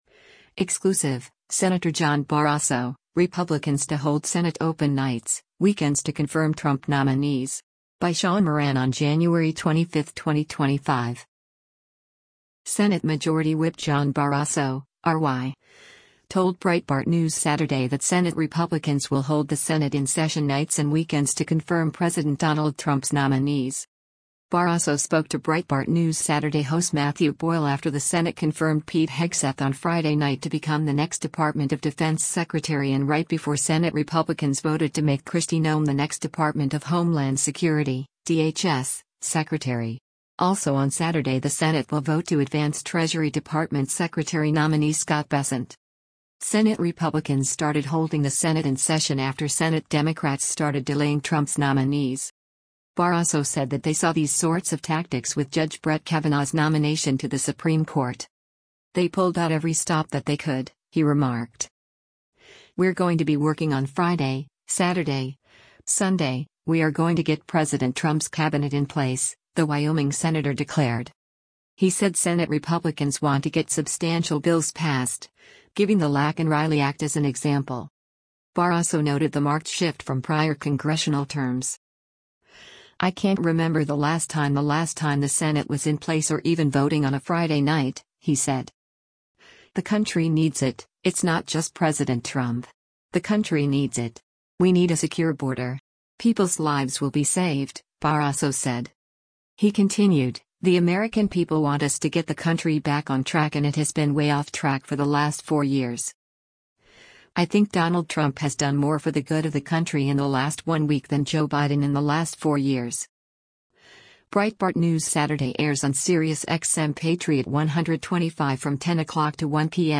Senate Majority Whip John Barrasso (R-WY) told Breitbart News Saturday that Senate Republicans will hold the Senate in session nights and weekends to confirm President Donald Trump’s nominees.